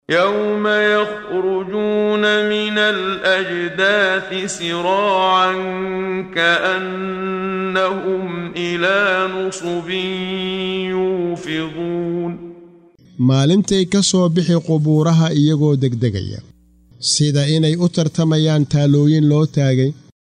Waa Akhrin Codeed Af Soomaali ah ee Macaanida Suuradda A-Macaarij ( Wadooyinka samada ) oo u kala Qaybsan Aayado ahaan ayna la Socoto Akhrinta Qaariga Sheekh Muxammad Siddiiq Al-Manshaawi.